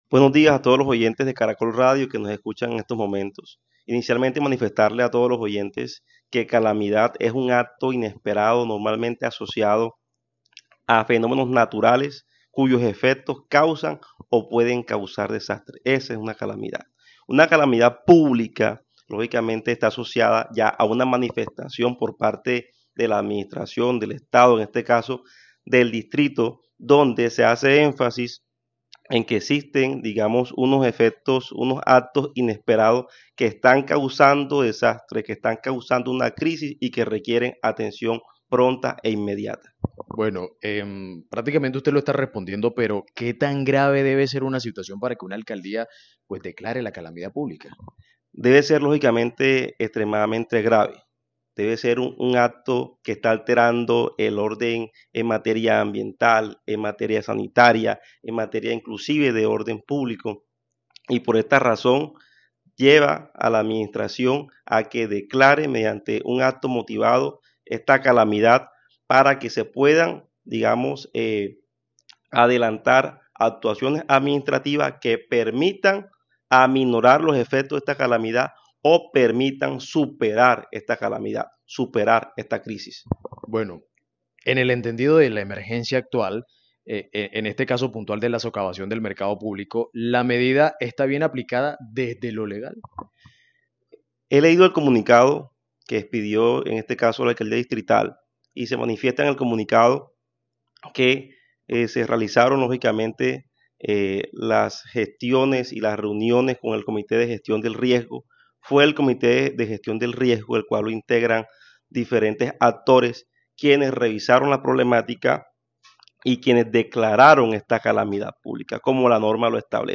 ¿Qué significa declarar Calamidad Pública? abogado explica la medida adoptada en Santa Marta